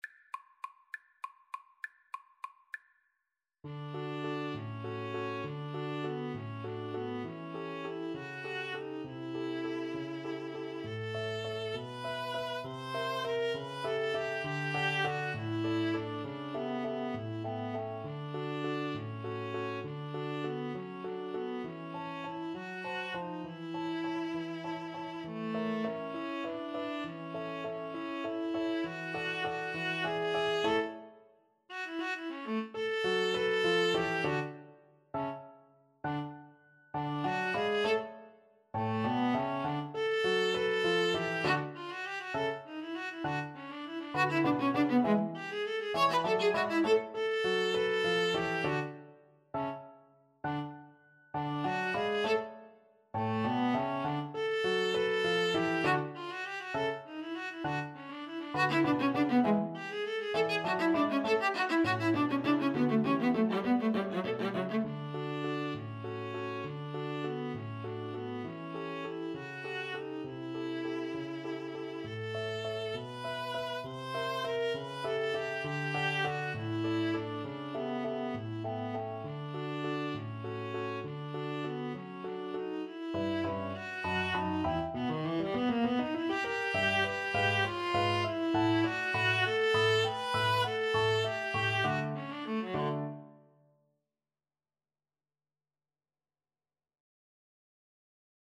3/4 (View more 3/4 Music)
~=200 One in a bar
D major (Sounding Pitch) (View more D major Music for Violin-Viola Duet )
Violin-Viola Duet  (View more Intermediate Violin-Viola Duet Music)
Classical (View more Classical Violin-Viola Duet Music)